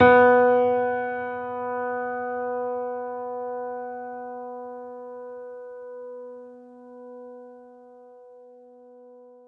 piano-sounds-dev
Vintage_Upright
b2.mp3